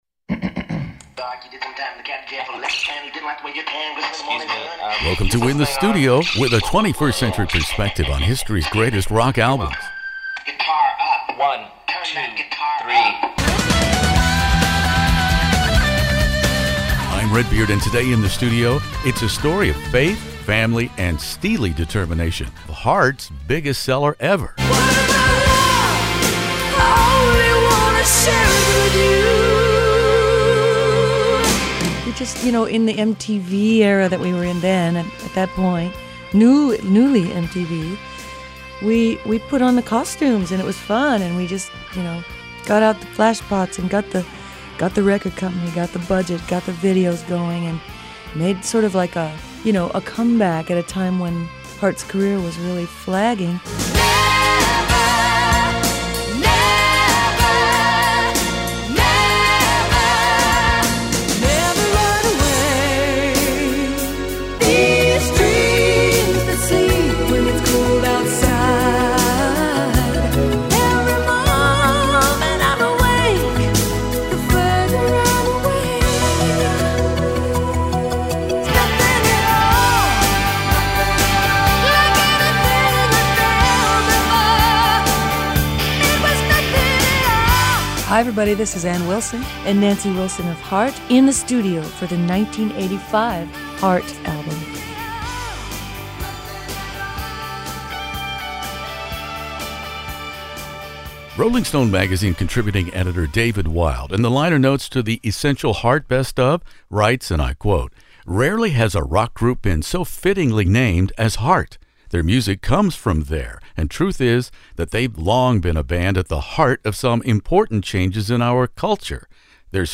Guitarist/singer Nancy Wilson and singing sistuh Ann Wilson join me here In the Studio for Heart.
Heart, the Rock and Roll Hall of Famers now in their fifth decade of making music together, this remarkable extended momentum for Heart ‘s career cardio health is possible in part because of the huge popularity of their biggest seller, Heart, which was released in June 1985. Ann Wilson and Nancy Wilson allow me to take their collective pulse on this effort in this revealing classic rock interview.